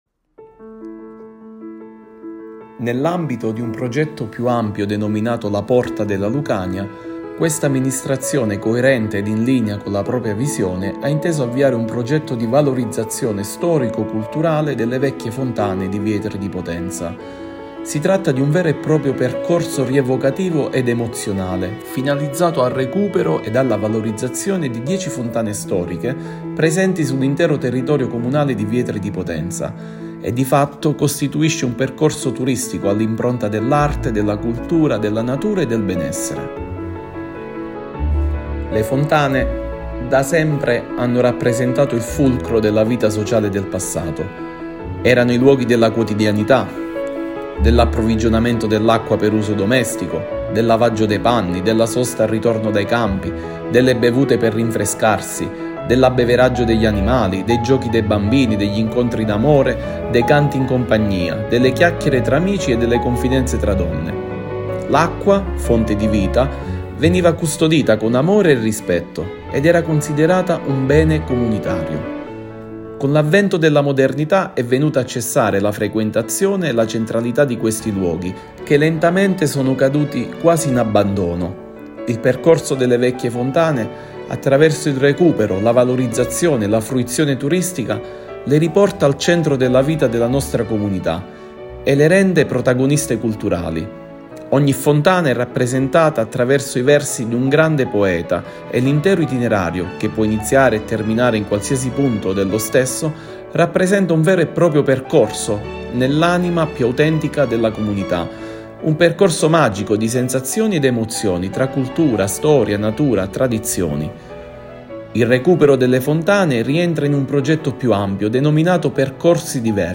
Ascolta l’audio del Sindaco Avv. Christian Giordano